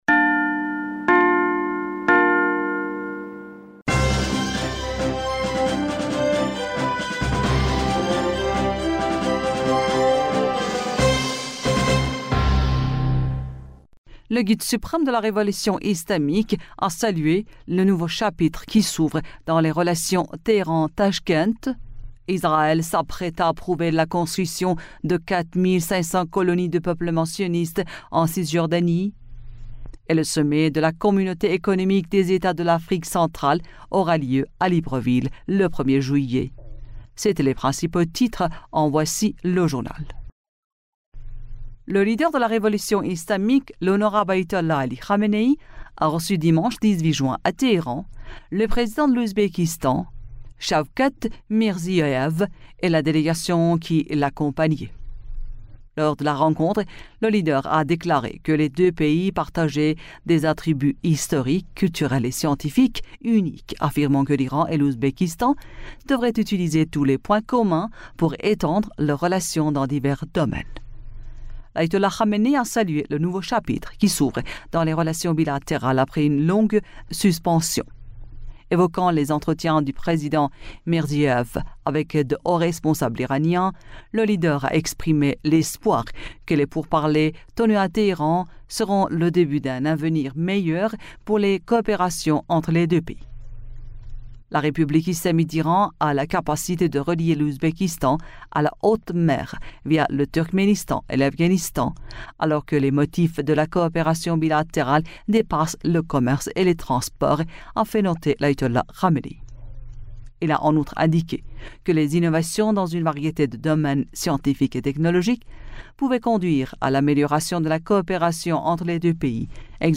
Bulletin d'information du 19 Juin 2023